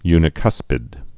(ynĭ-kŭspĭd)